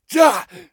pain_4.ogg